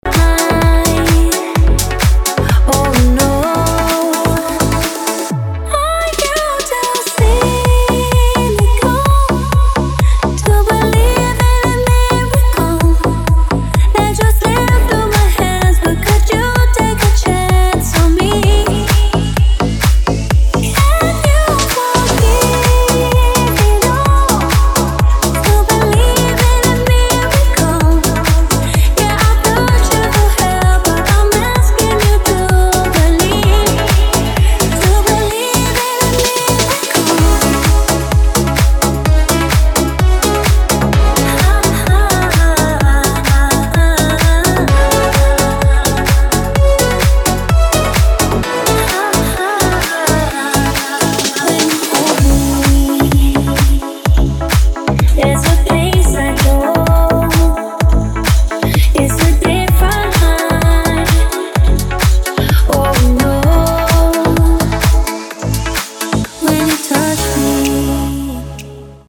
NEW BANGER REMIX!
BPM: 128 Time